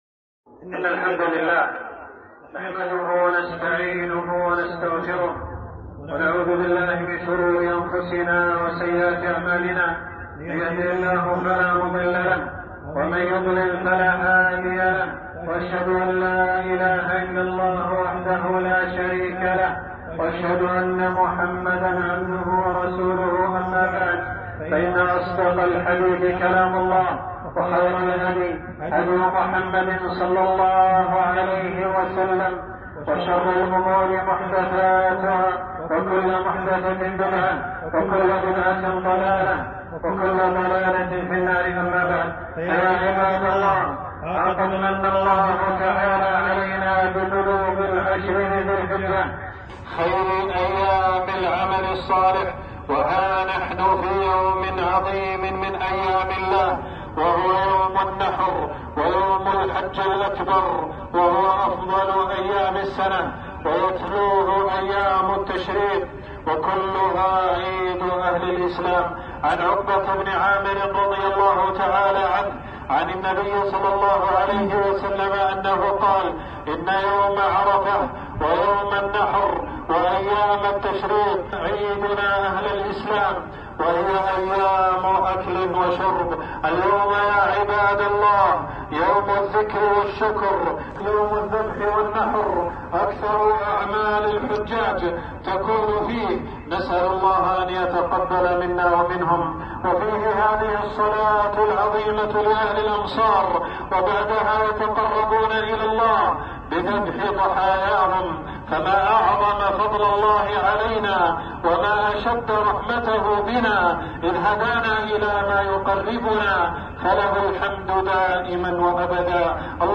خطبة عيد الأضحى 1442هـ